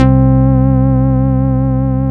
SYN STRANG05.wav